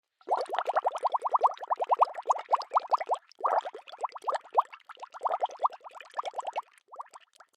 Free SFX sound effect: Milk Bubbles.
Milk Bubbles
yt_n4tMq4wQWfM_milk_bubbles.mp3